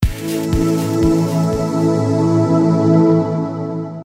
lb_achievement.wav